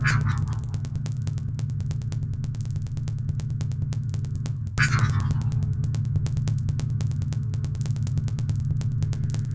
Abstract Rhythm 37.wav